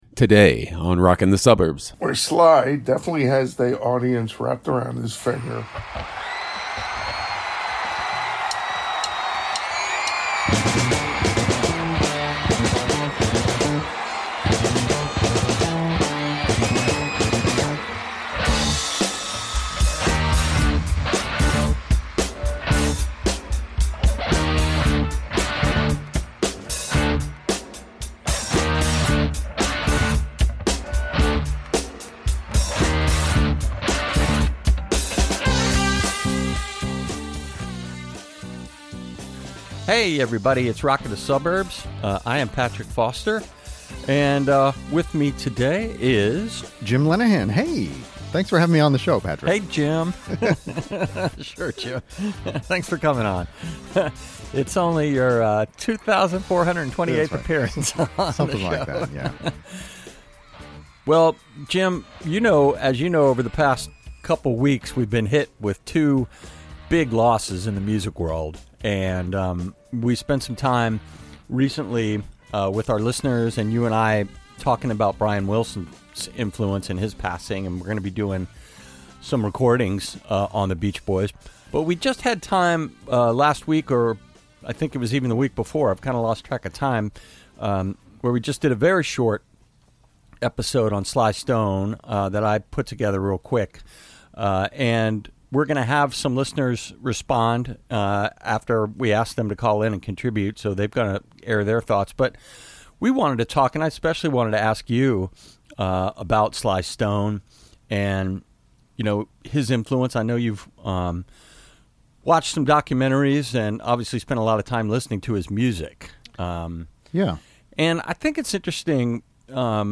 Come join two (not so) ordinary family guys in the basement (or on the deck) as they talk about their lifelong obsessions with music.